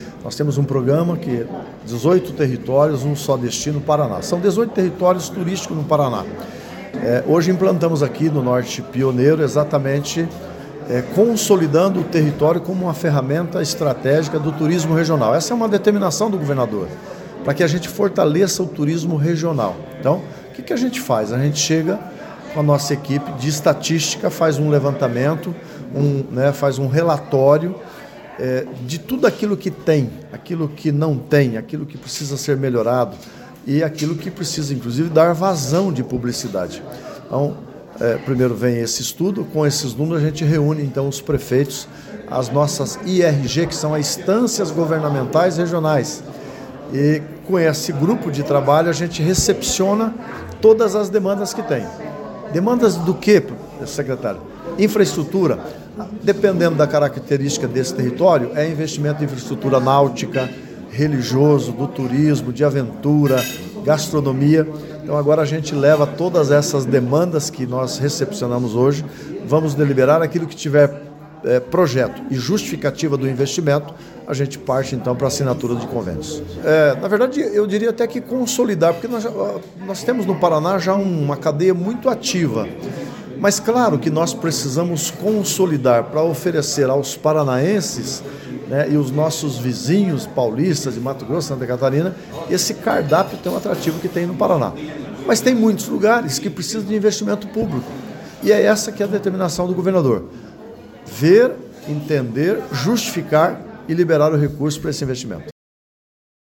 Sonora do secretário do secretário de Turismo, Leonaldo Paranhos, sobre a inclusão do Norte Pioneiro na rede de territórios turísticos do Paraná durante a 30ª Fetexas